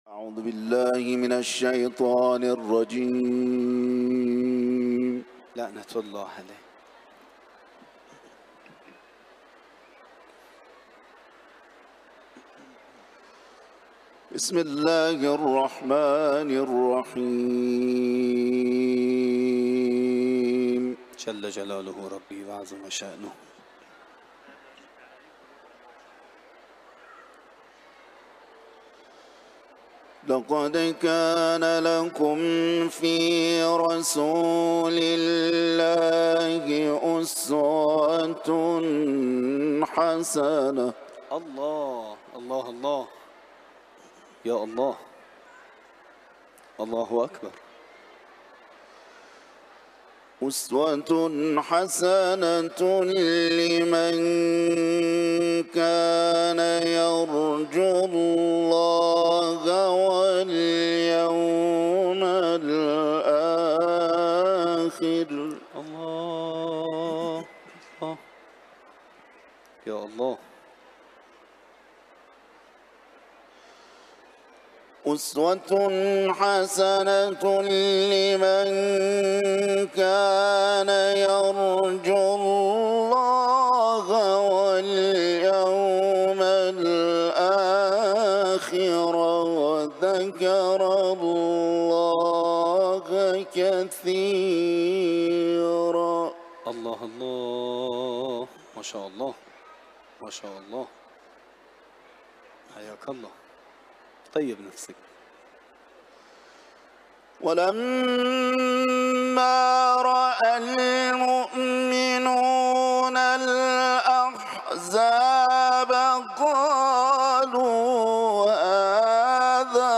Kur’an-ı Kerim tilaveti
İranlı Uluslararası Kur’an kârisi